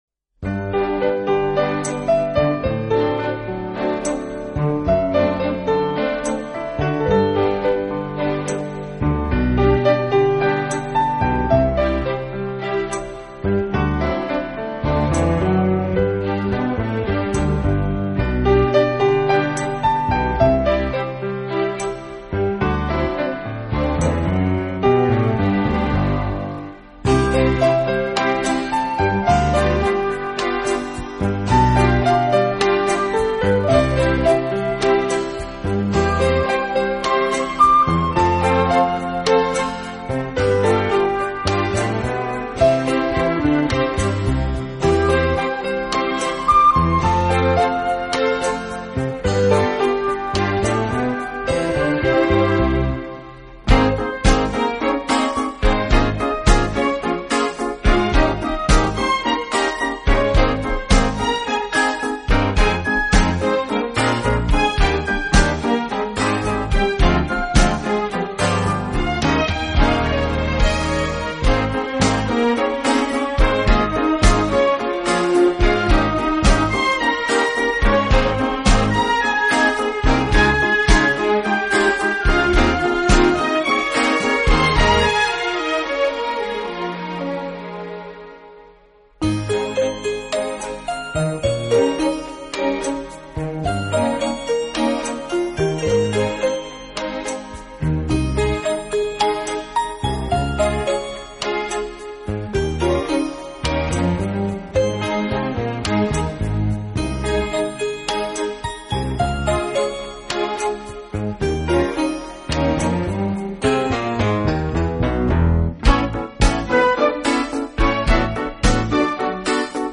音乐类型：New Age/Classicmusic/Instrumental